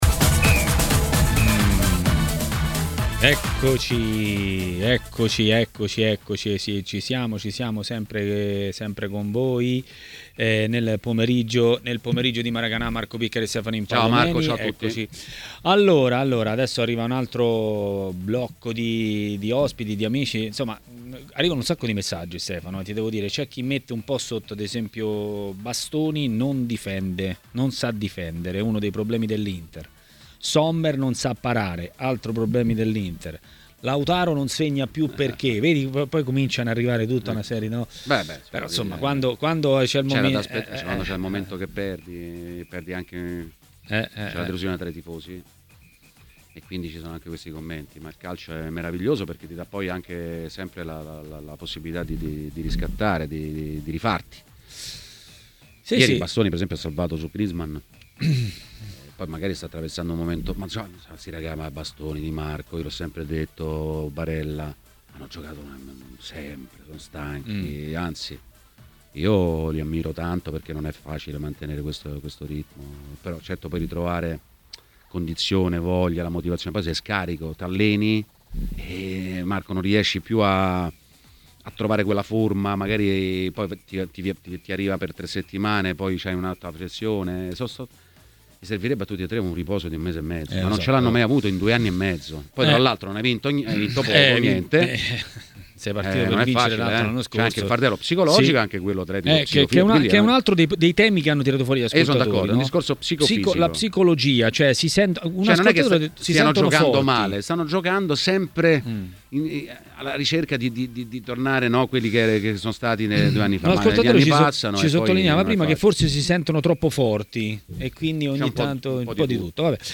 A parlare dei temi del giorno a TMW Radio, durante Maracanà, è stato l'ex calciatore e tecnico Andrea Mandorlini.